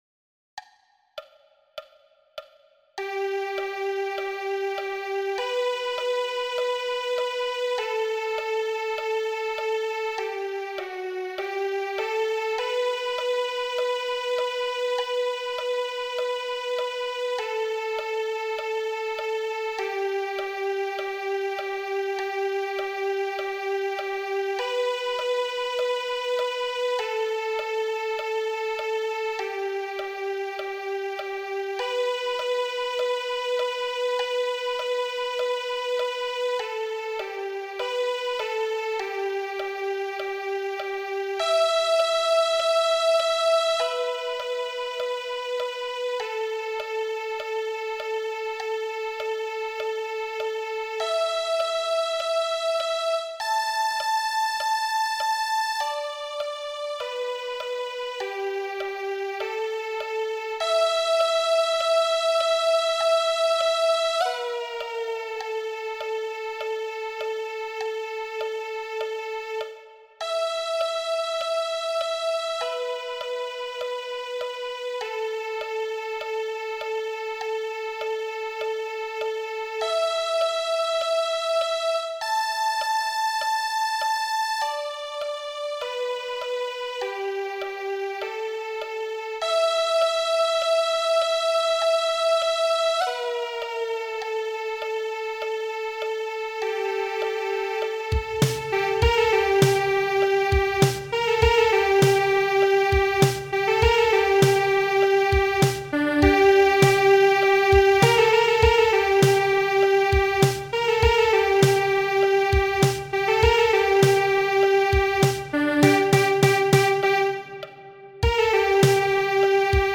Hello Cello Orkest Het Hello Cello Orkest is het grote zoemende cello-orkest van de Cello Biënnale en biedt plaats aan 140 cello spelende kinderen en jongeren tot 18 jaar uit heel Nederland.